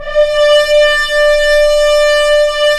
Index of /90_sSampleCDs/Roland L-CD702/VOL-2/BRS_F.Horns FX+/BRS_FHns Mutes
BRS F.HRNS0B.wav